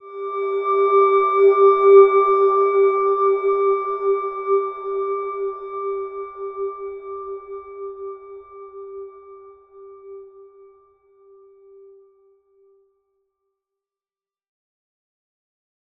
Silver-Gem-G4-f.wav